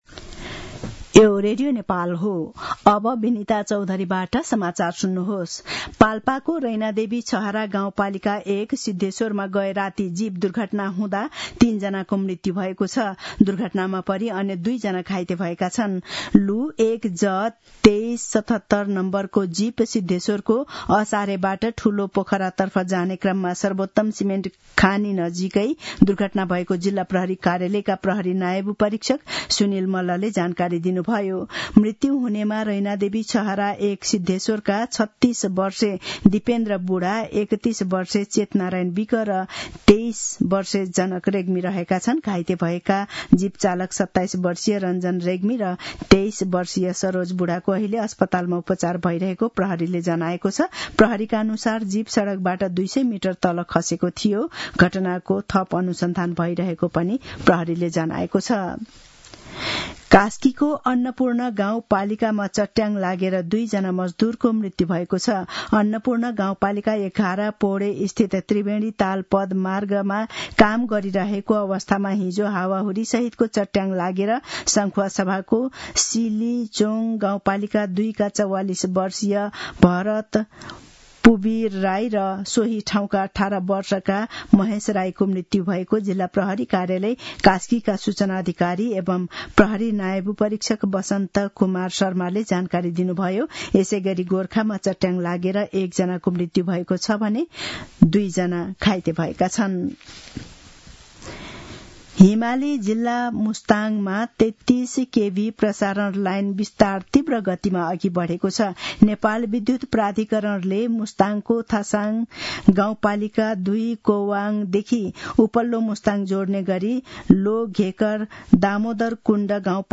मध्यान्ह १२ बजेको नेपाली समाचार : १५ वैशाख , २०८२